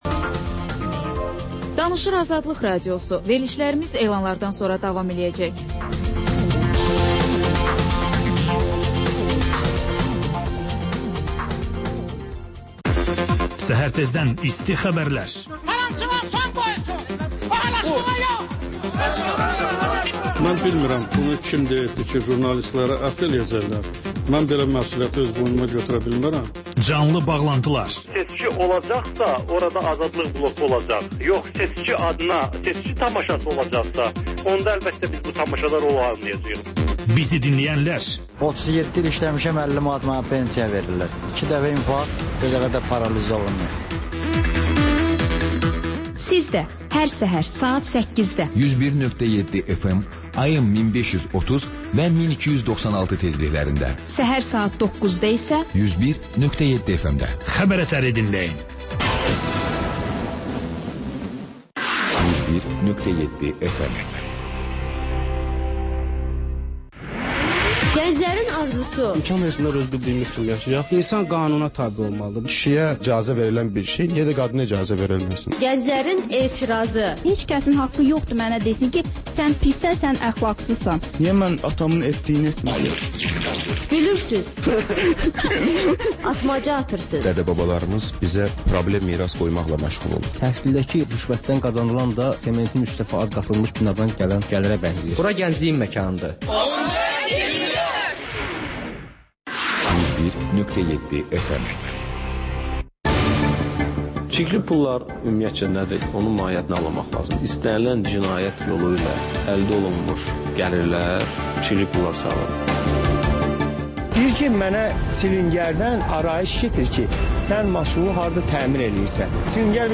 Həftə boyu efirə getmiş CAN BAKI radioşoularında ən maraqlı məqamlardan hazırlanmış xüsusi buraxılış (TƏKRAR)